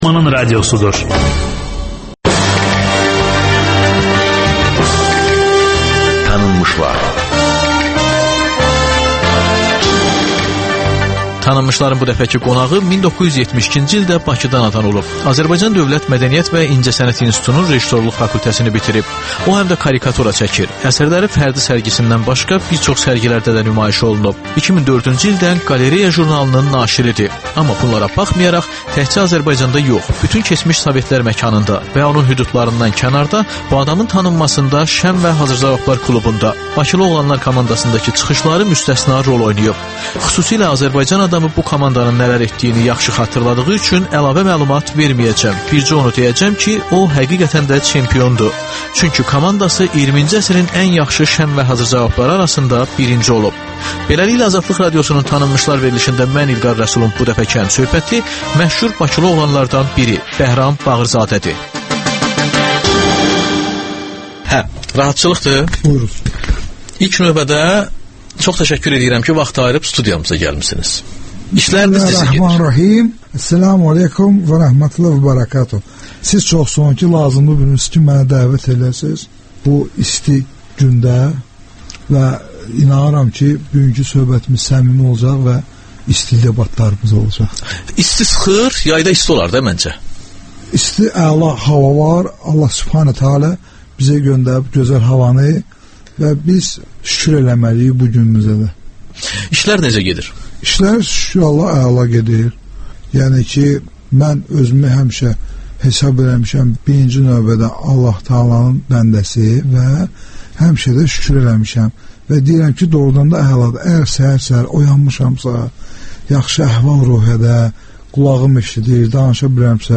Ölkənin tanınmış simaları ilə söhbət Təkrar